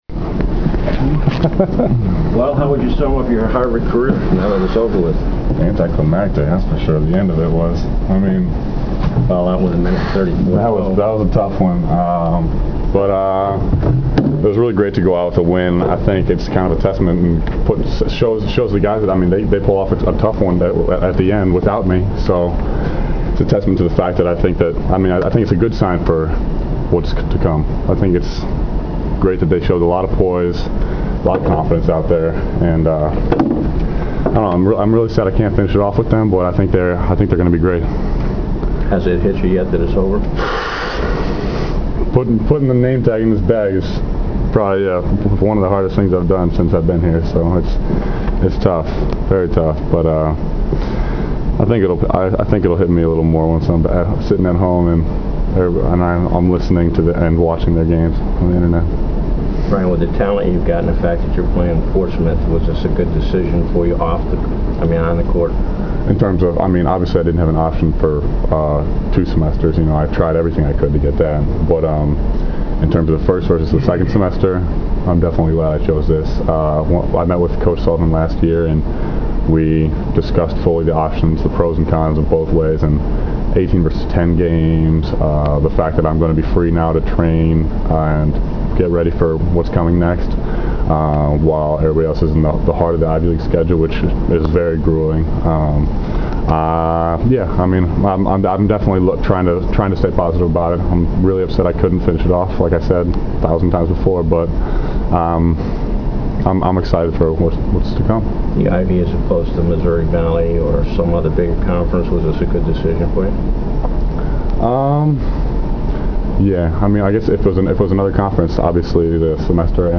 interview in a towel in front of his locker.